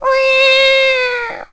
Ironically, in The Wind Waker , during the sidequest in Windfall Island where the hero Link quietly follows Mila , whenever she says "Who is that?", he meows like a Cat , technically mimicking the pet counterpart.
WW_Link_Meow.wav